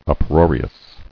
[up·roar·i·ous]